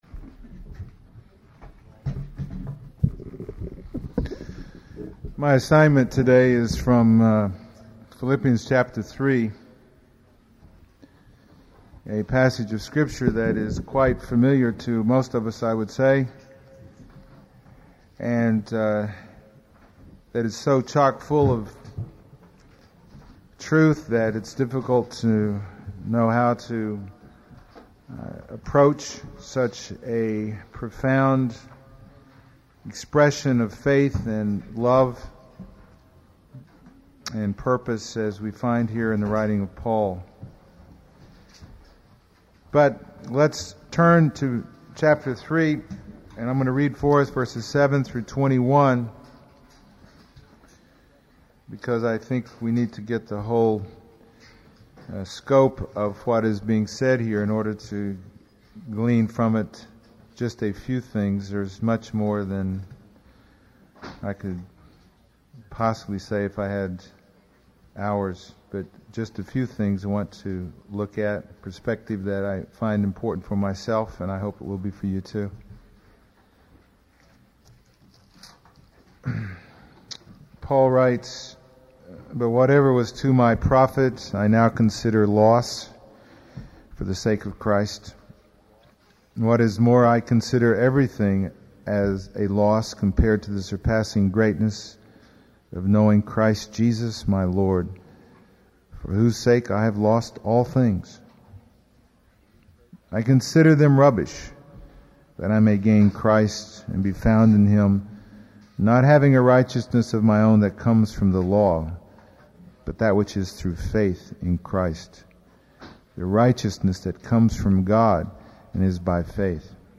No Series Passage: Philippians 3:7-21 Service Type: Sunday Morning %todo_render% « Reaction to Tragedy